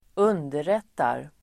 Uttal: [²'un:deret:ar]